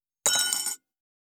253,ガラスがこすれあう擦れ合う音,カトラリーの音,食器の音,会食の音,食事の音,カチャン,コトン,効果音,環境音,BGM,カタン,チン,コテン,
コップ